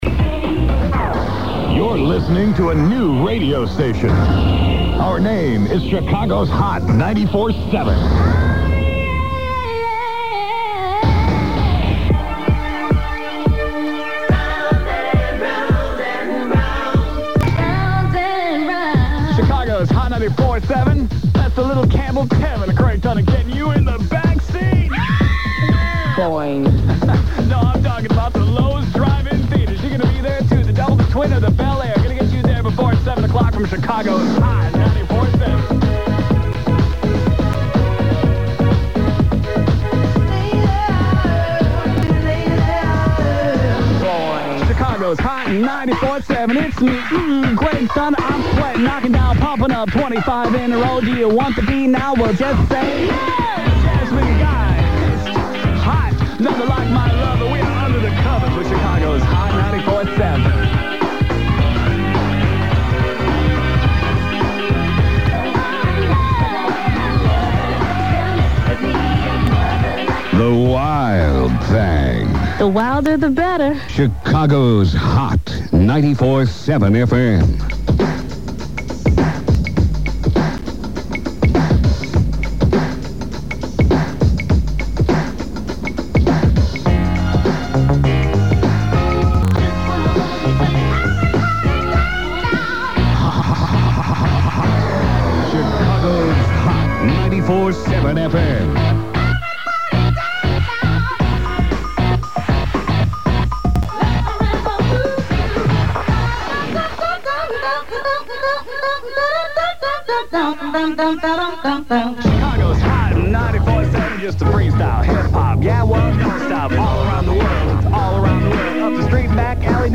Chicago’s Hot 94-7 FM represented the high-energy, but short-lived aftermath of “Hell 94.7”, and the final (as of this writing) attempt of this frequency to compete with heritage Rhythmic CHR WBBM-FM (B96).